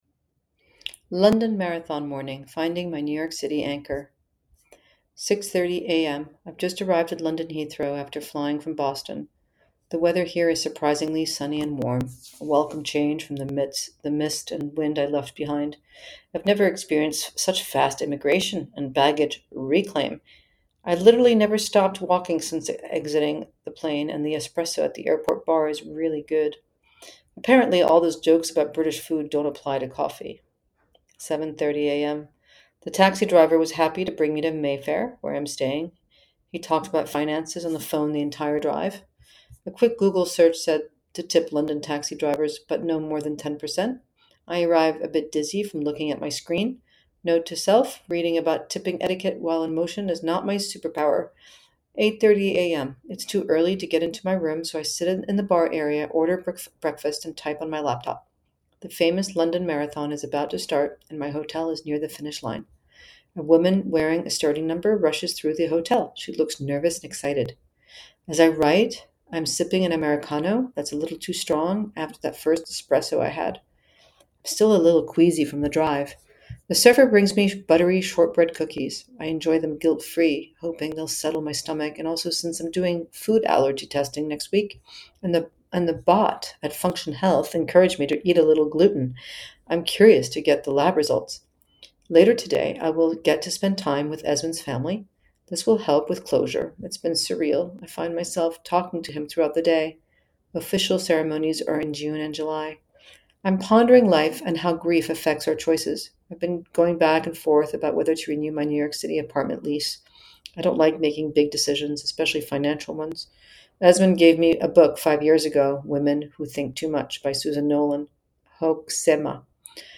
A narrated essay from The Pressures of Privilege.